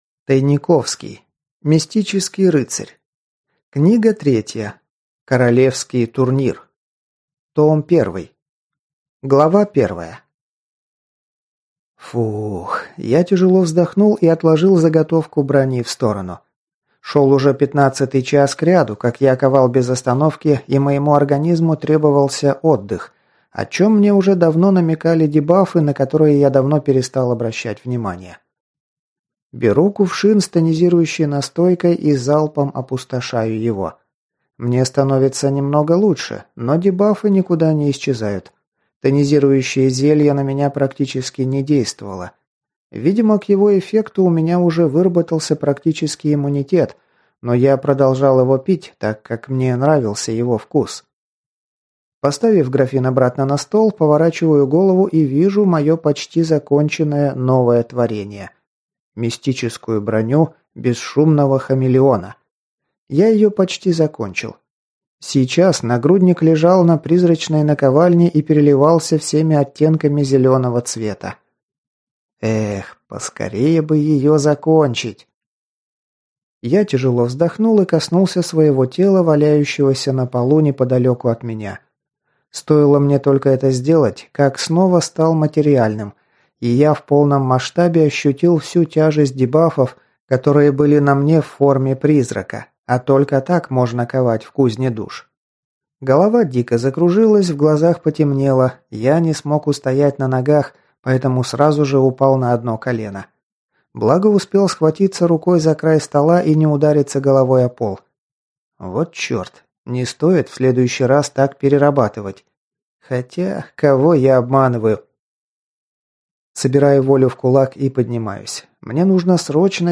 Аудиокнига Королевский турнир. Том 1 | Библиотека аудиокниг